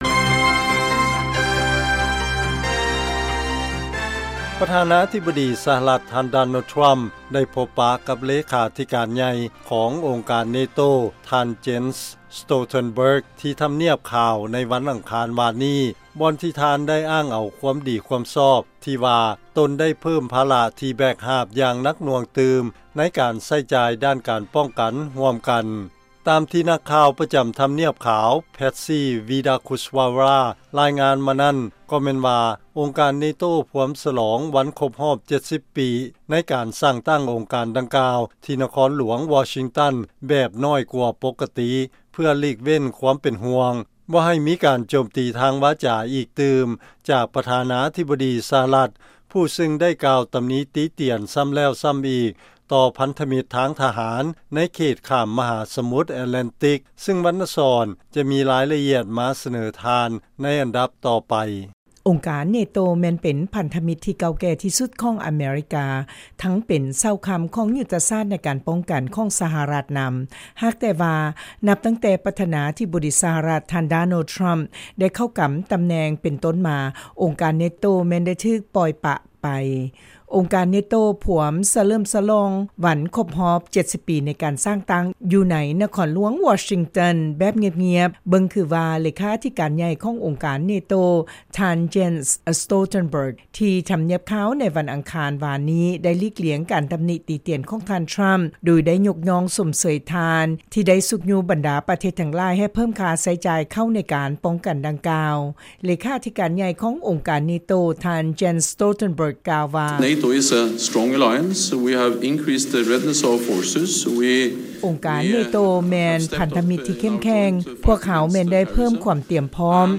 ລາຍງານກ່ຽວກັບການທີ່ທ່ານທຣຳ ຮຽກຮ້ອງໃຫ້ເພີ້ມຄ່າໃຊ້ຈ່າຍເຂົ້າສົມທົບອົງການເນໂຕ ເພືອການປ້ອງກັນຄວາມປອດໄພ